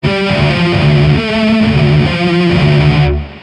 描述：用雅马哈yz35制作的真正的合成器，不是midi电吉他的声音，但使用的乐器是合成器。
Tag: 140 bpm Rock Loops Guitar Electric Loops 590.67 KB wav Key : Unknown Magix Music Maker